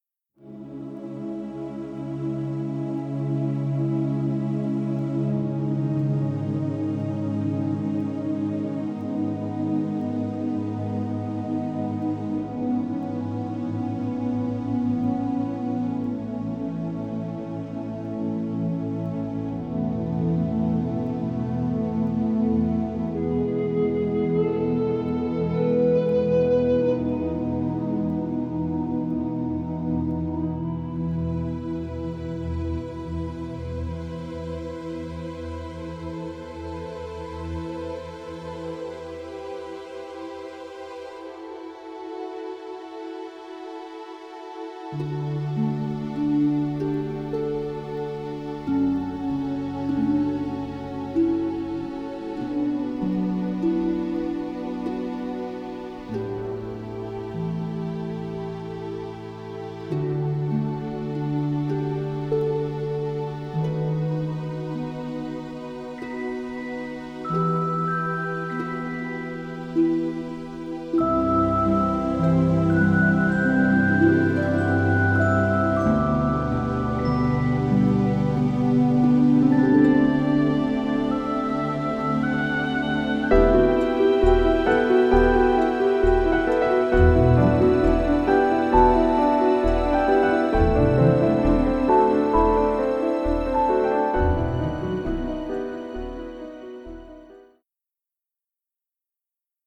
sensitive and gentle score
gentle transparency and full orchestra-like ambience